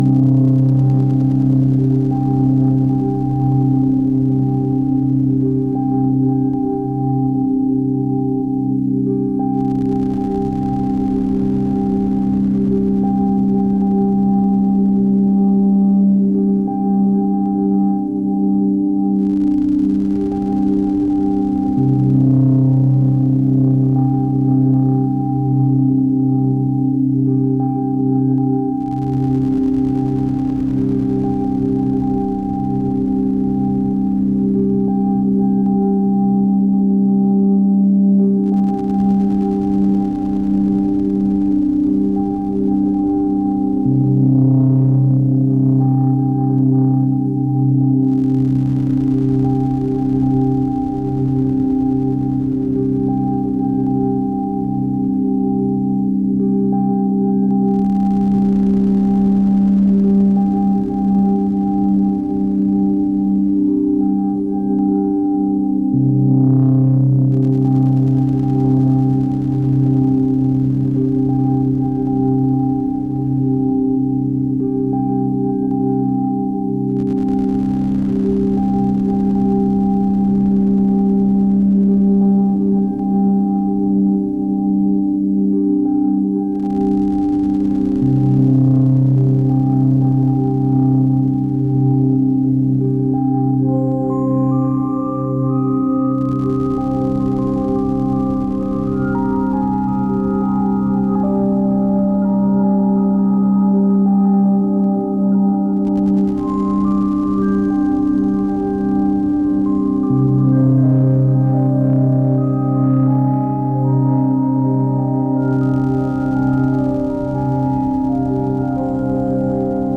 nothing town Posts Photos Music About Posts Photos Music About colding_3 Modular Synth October 18, 2025
modular ambient cold sketch